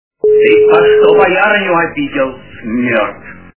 » Звуки » Из фильмов и телепередач » Иван Васильевич меняет профессию - Ты по што боярыню обидел... Смерд!
При прослушивании Иван Васильевич меняет профессию - Ты по што боярыню обидел... Смерд! качество понижено и присутствуют гудки.